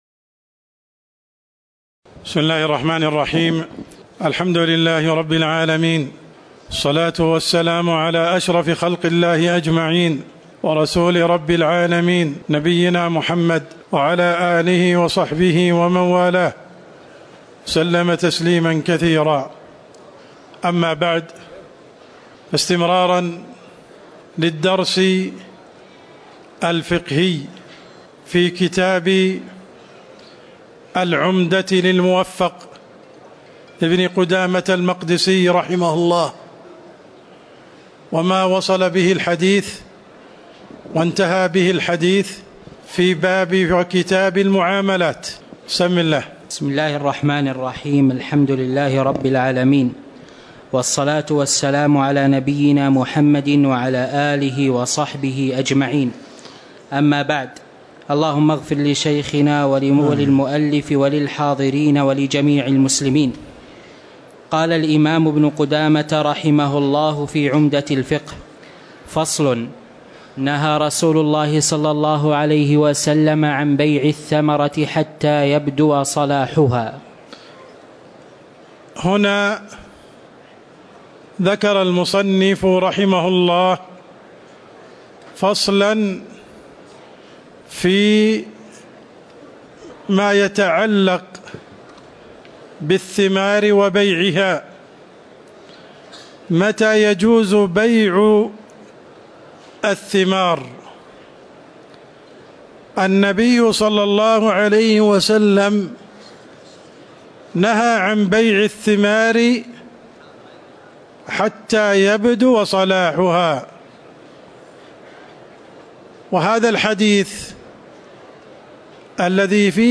تاريخ النشر ٢٥ جمادى الآخرة ١٤٤١ هـ المكان: المسجد النبوي الشيخ: عبدالرحمن السند عبدالرحمن السند فصل نهى رسول الله عن بيع الثمرة حتّى يبدو صلاحها (06)